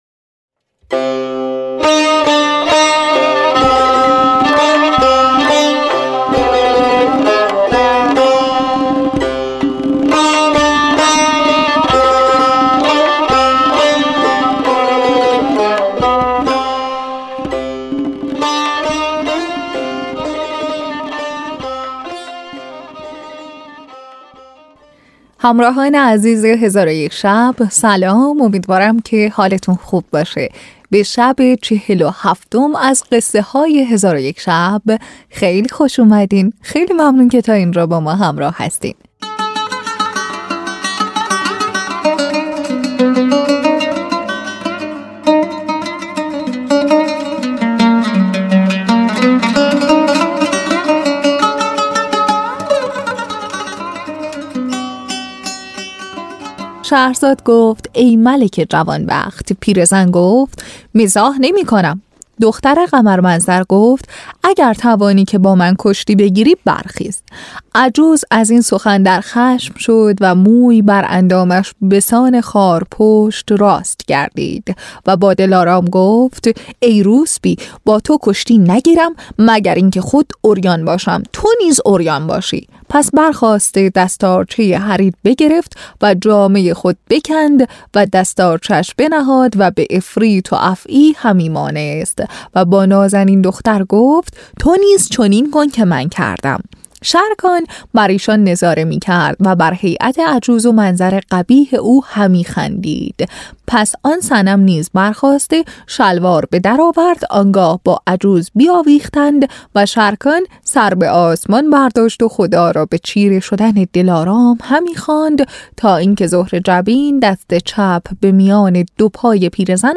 این اپیزود، طعمی دیگر از قصه گویی کلاسیک را به کام شنوندگان می‌نشاند.
تهیه شده در استودیو نت به نت